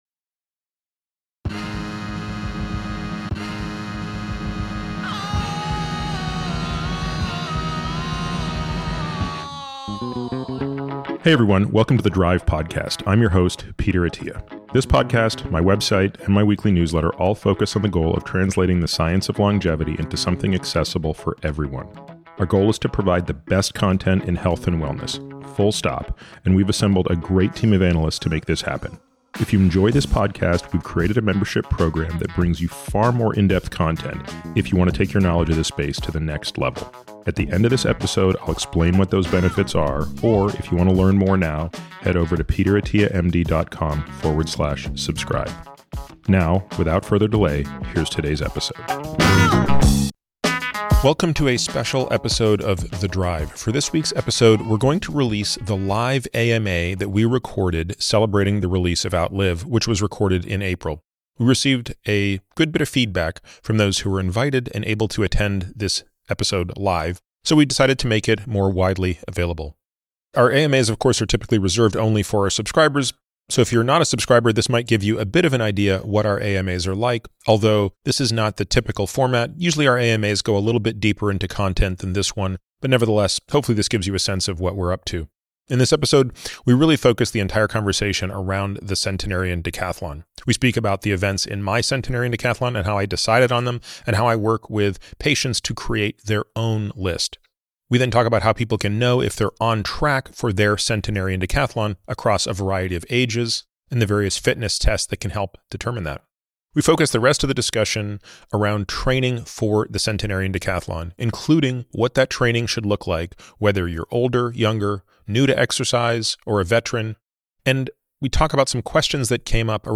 In this special episode filmed live in front of readers of Outlive, Peter answers questions revolving around his concept of the centenarian decathlon. He starts by defining the “marginal decade” and how that shapes his training for the events and activities that make up his personal centenarian decathlon.